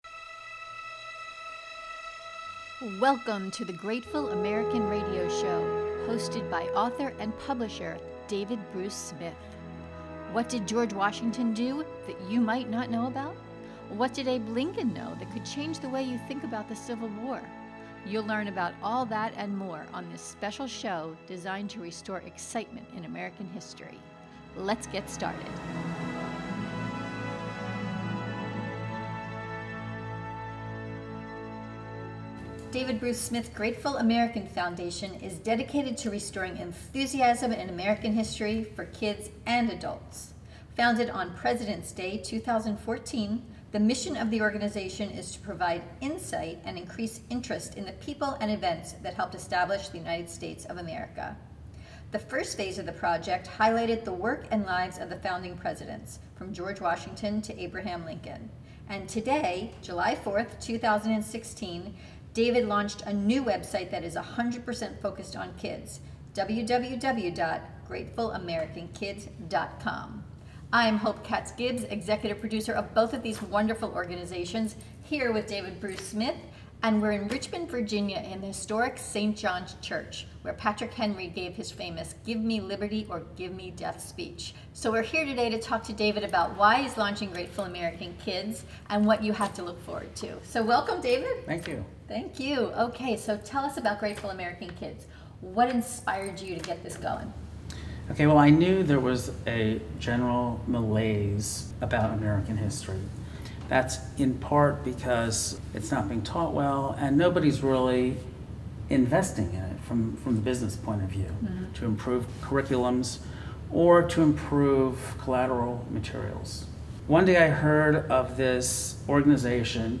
Scroll down for our Q&A. Download our podcast interview now!